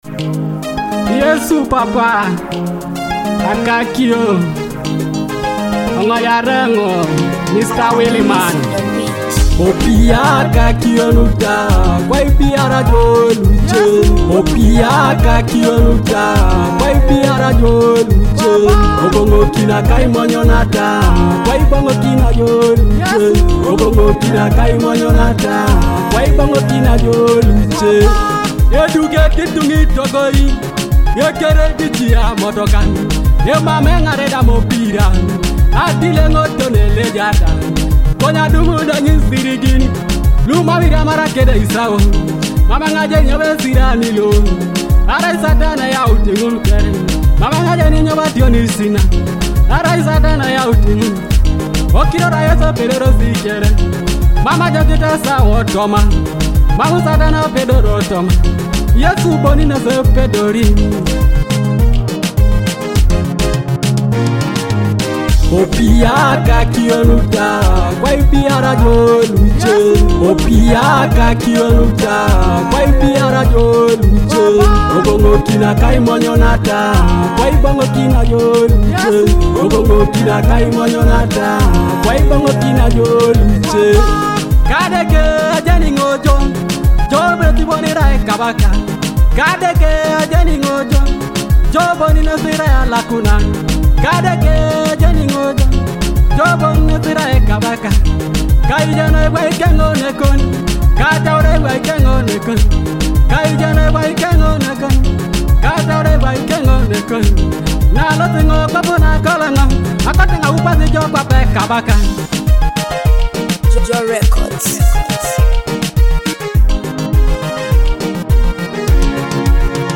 With its soulful beats and deep prayerful message